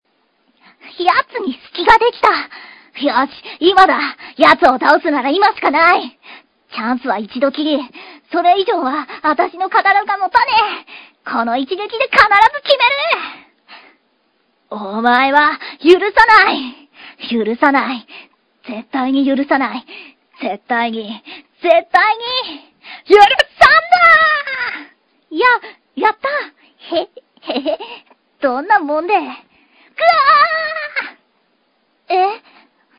主にみみみの台詞ですが、シッポウ君のところも読んでるせいでわけわからんね（＾＾；　「ぐあああっ！！」　と絶叫する機会は日常めったにないことなので、どうしても読みたかったんです。